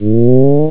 sfx_heal.wav